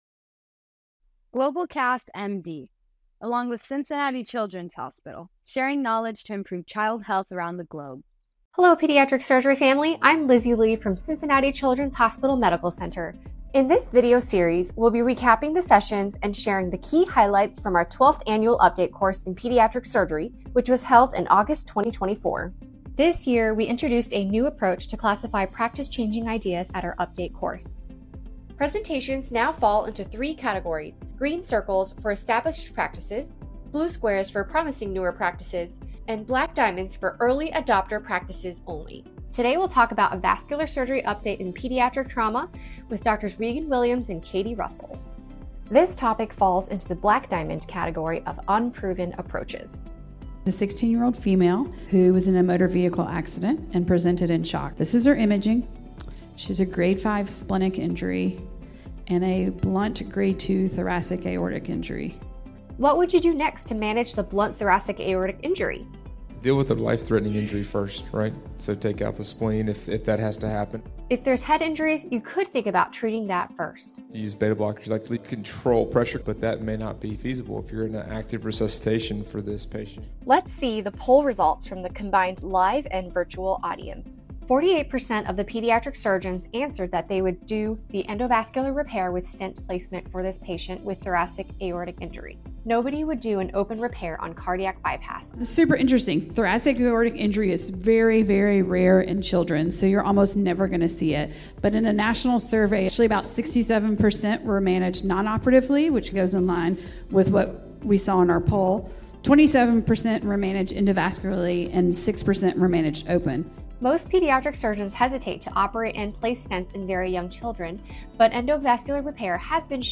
In this 2024 Update Course Rewind, pediatric surgeons Drs.